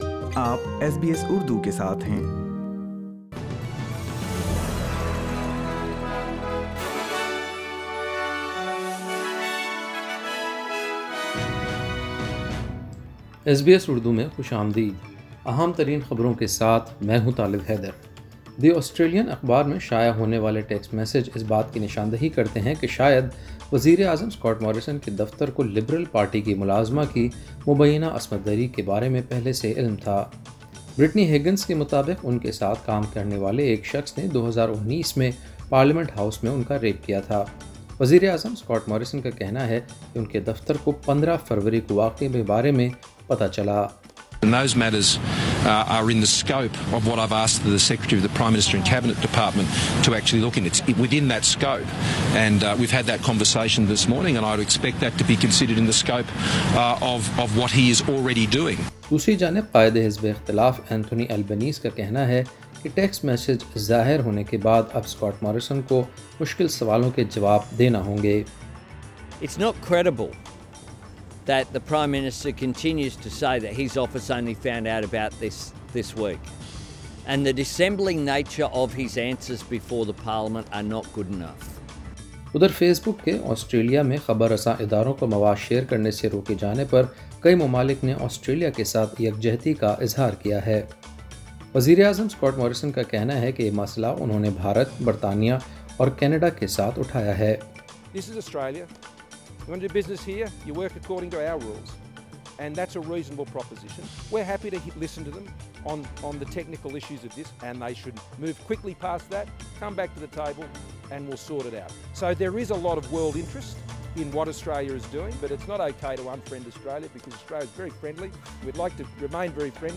فیس بُک کے خبر رساں اداروں کے مواد پر پابندی کے معاملے پر کئی ممالک کا آسٹریلیا کے ساتھ اظہارِیکجہتی، وفاقی خزانچی جاش فرائڈنبرگ کا کہنا کہ اس معاملے پر فیسبک کے بانی سے دوبارہ بات کریں گے اور وکٹوریہ میں کرونا وائرس کے تین نئے کیسز۔ سنئے اردو خبریں۔